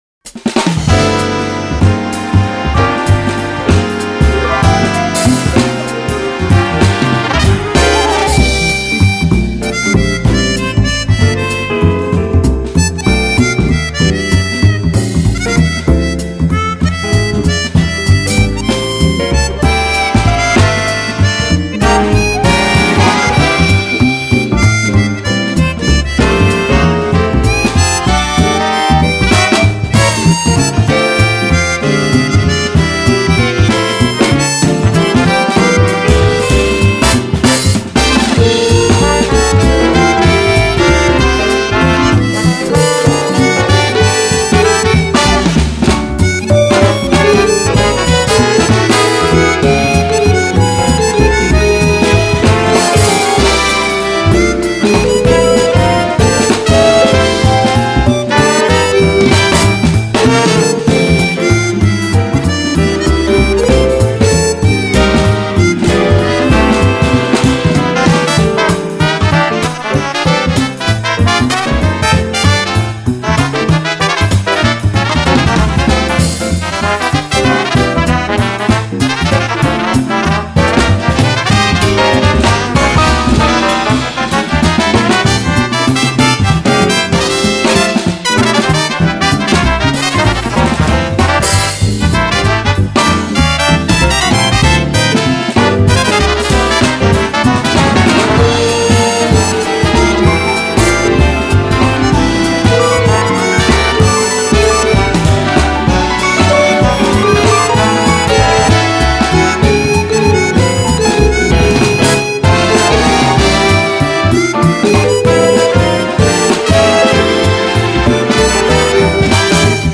재즈곡입니다